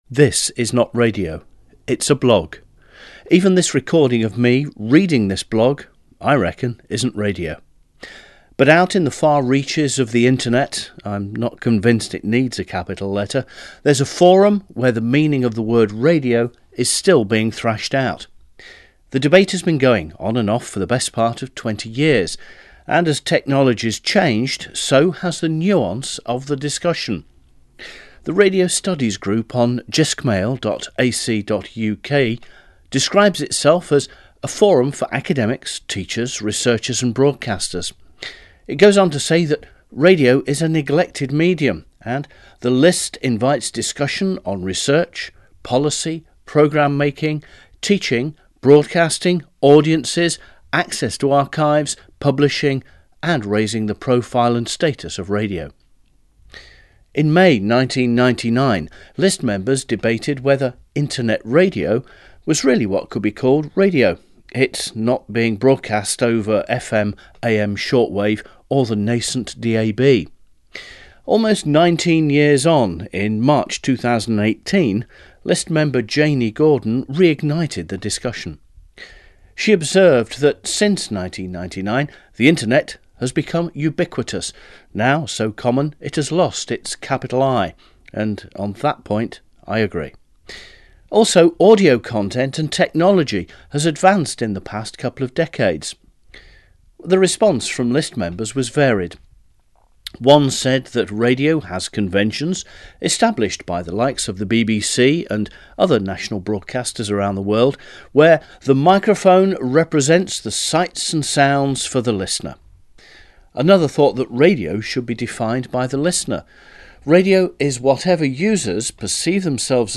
Even this recording of me reading this blog – I reckon – isn’t radio: